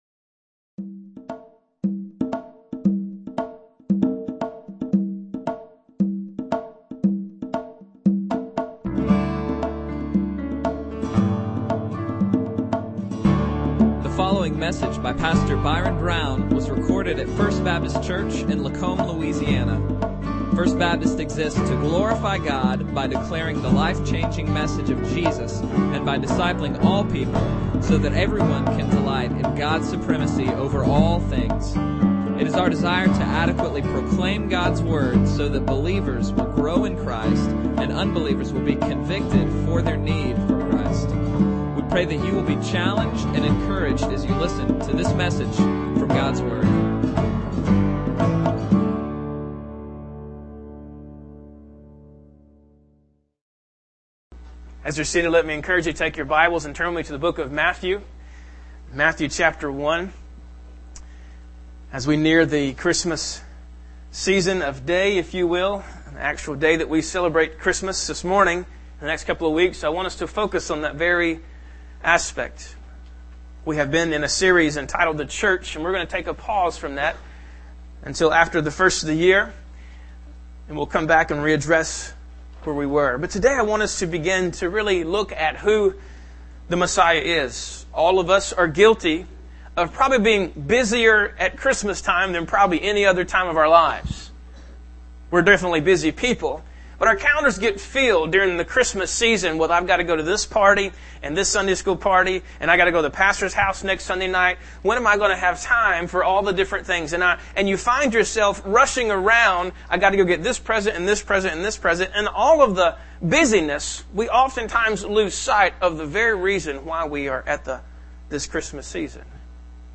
Bible Text: Matthew 1:1-17 | Preacher